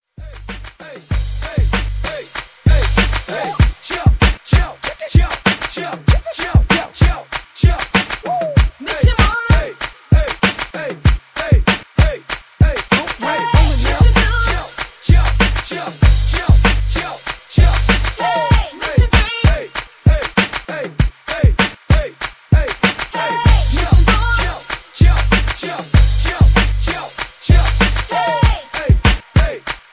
(Key-Gm) Karaoke MP3 Backing Tracks
Just Plain & Simply "GREAT MUSIC" (No Lyrics).